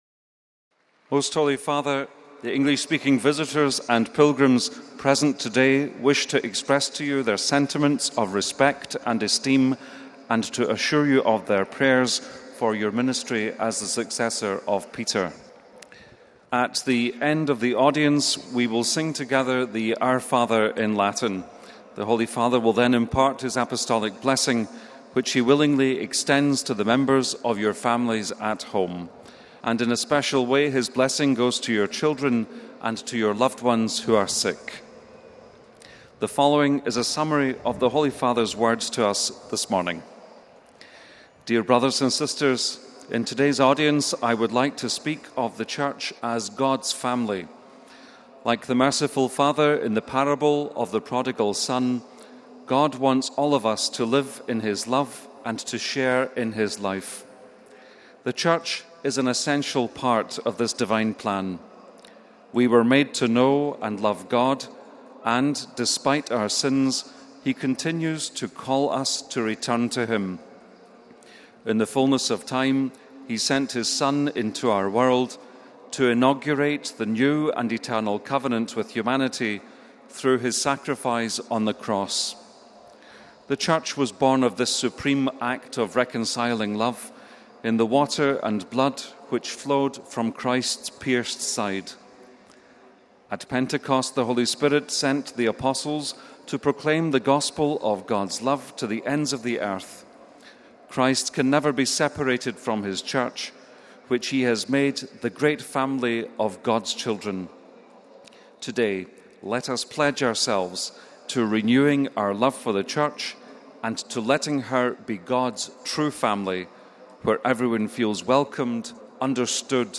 29 May, 2013 - Pope Francis held his weekly general audience in the open in St. Peter’s Square in the Vatican on Wednesday.
Summaries of his discourse were then read out by aides in various languages, including in English. The aide began by first greeting the Pope on behalf of the English-speaking pilgrims.